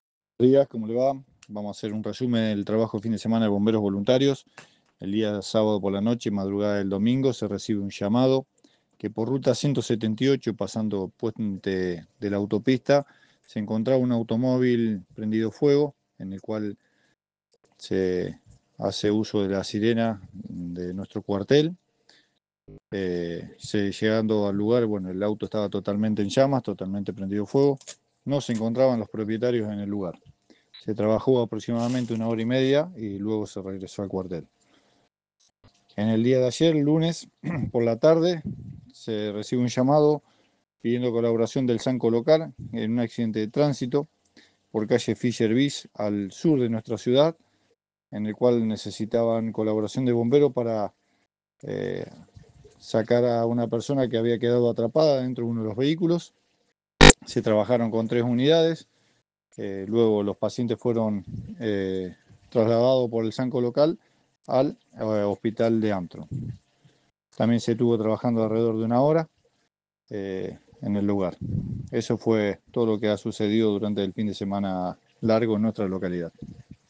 A continuación escucharemos el informe